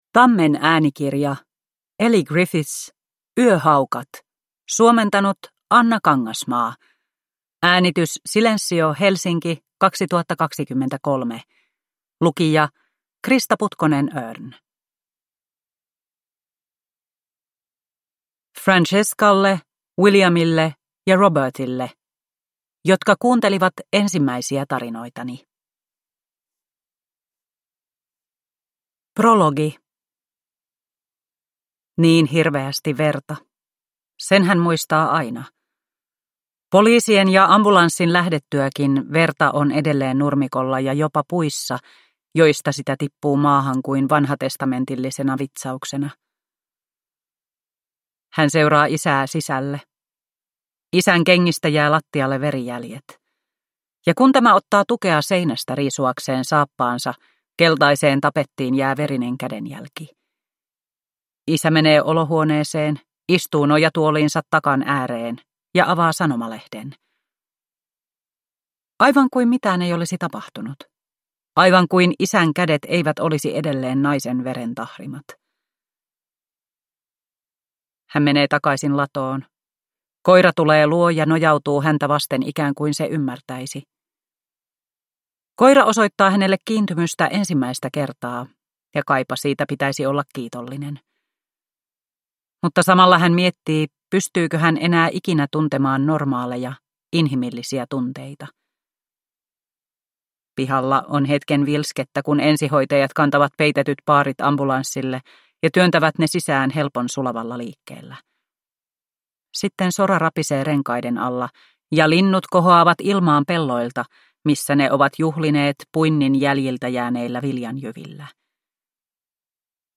Yöhaukat – Ljudbok – Laddas ner